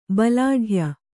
♪ balāḍhya